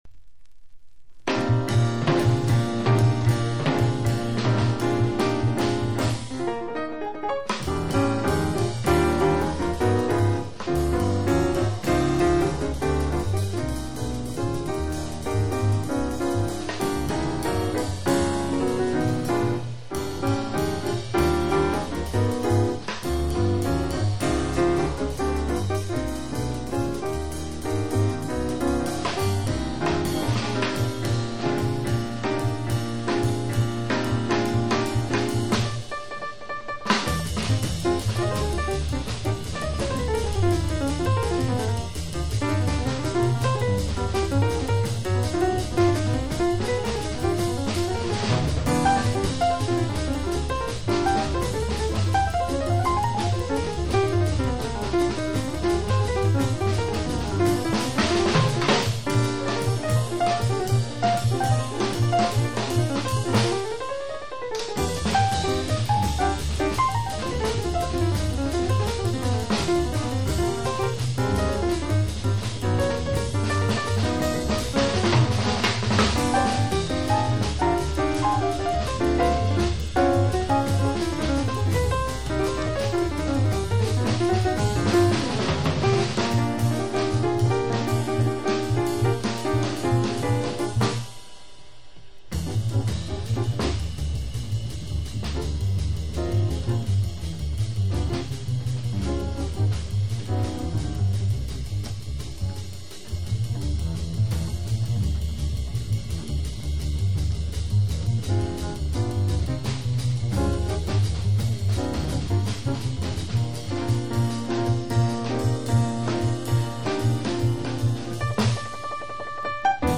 （プレス・小傷によりチリ、プチ音ある曲あり）※曲名をクリックする…